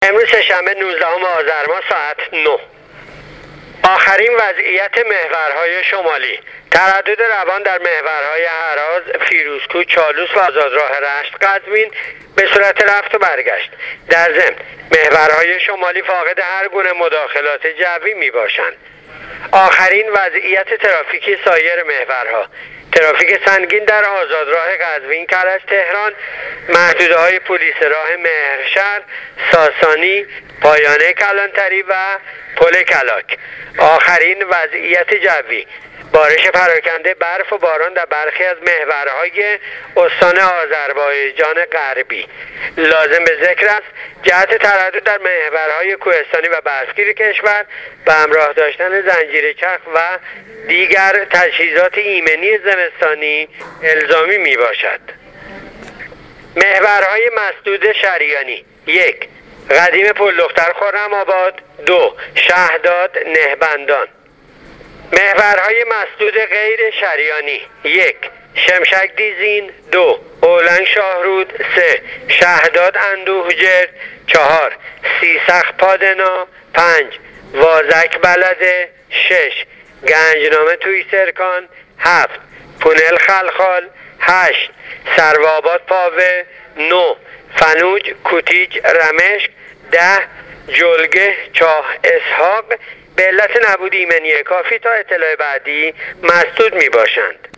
گزارش رادیو اینترنتی از آخرین وضعیت ترافیکی جاده‌ها تا ساعت ۹ نوزدهم آذر ۱۳۹۸: